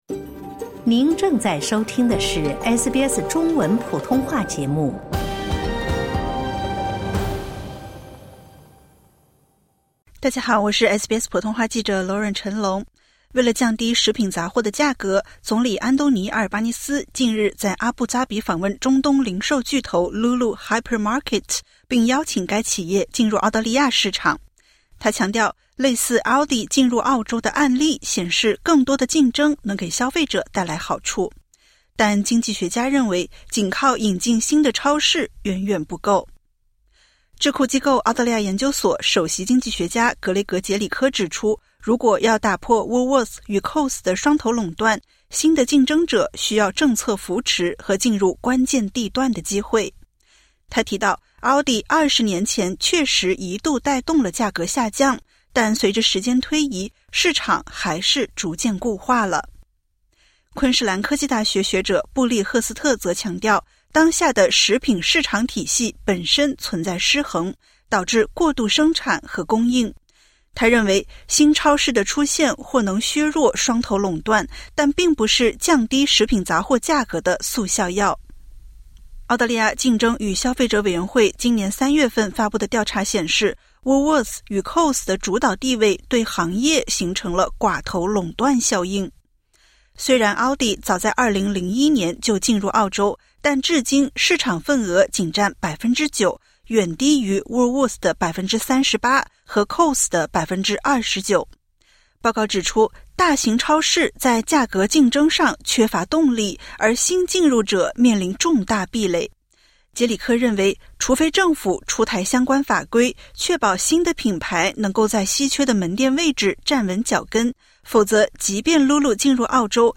总理阿尔巴尼斯近日抛出新构想：通过引进中东零售巨头Lulu Hypermarket进入澳洲市场，打破Coles与Woolworths的长期双头垄断。然而，专家与政界声音普遍认为，单靠“引进新玩家”并不足以解决深层次问题。点击 ▶ 收听完整报道。